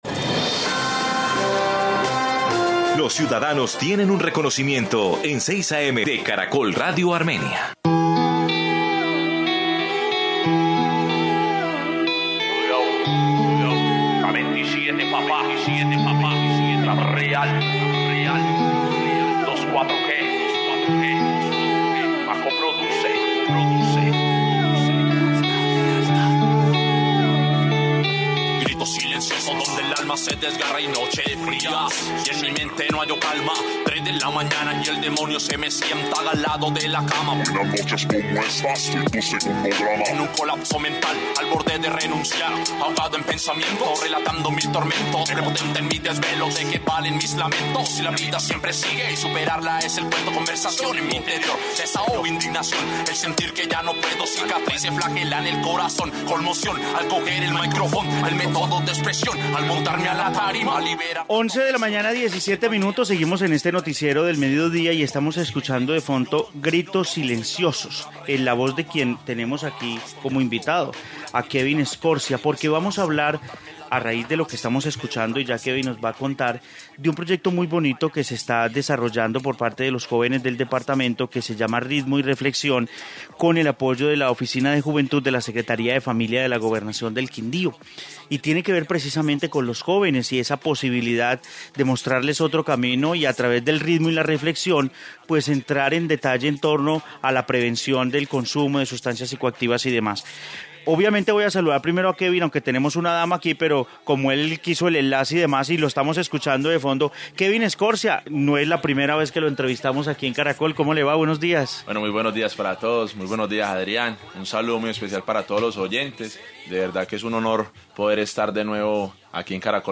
Informe Ritmo y Reflexión en el Quindío